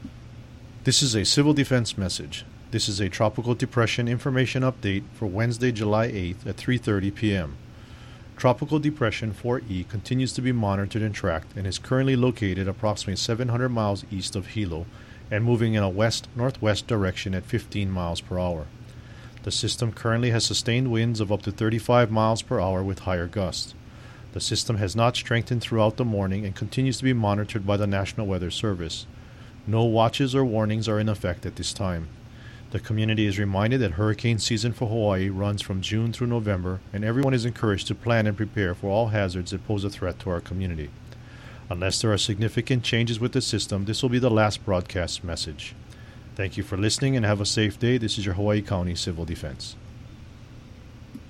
Hawaii County Civil Defense 3:30 p.m. audio update